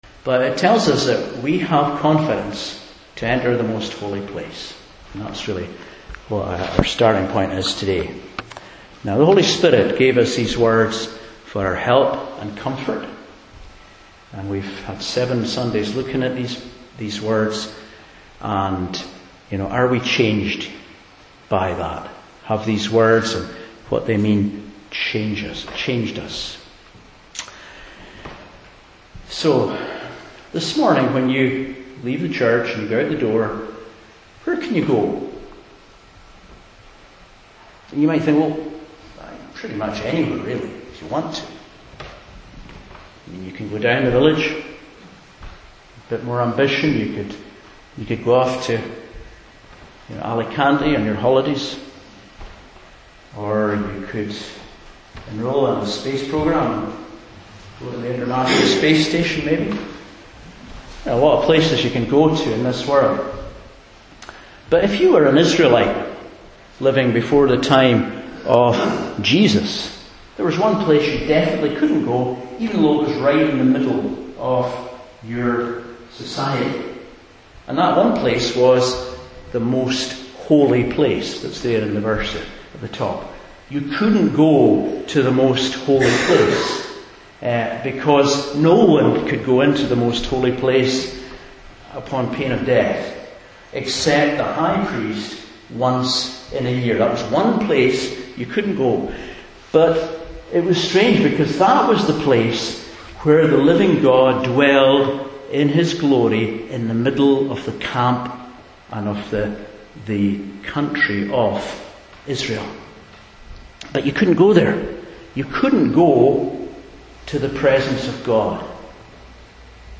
Hebrews 10:19-22 Service Type: Morning Service Today we marvel at the love Jesus has shown to us by serving as our Great Priest.